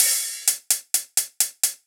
Index of /musicradar/ultimate-hihat-samples/128bpm
UHH_ElectroHatB_128-03.wav